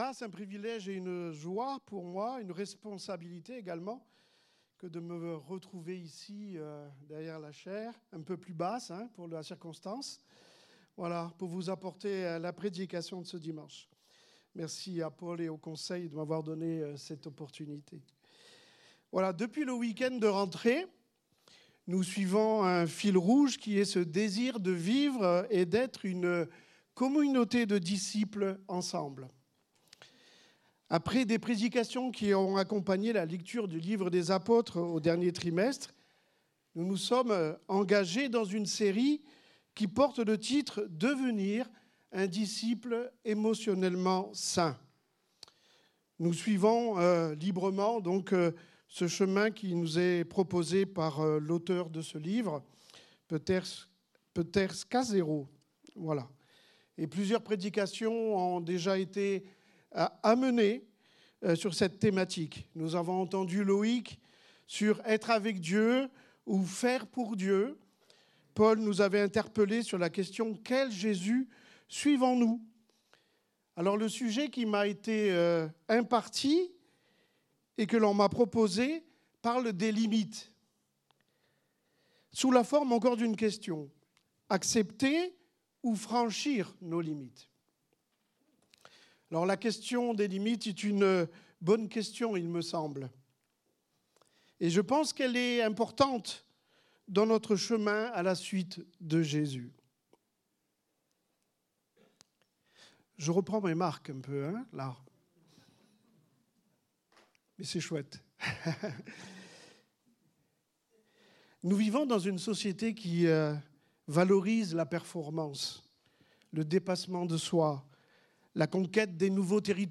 Culte du dimanche 02 mars 2025, prédication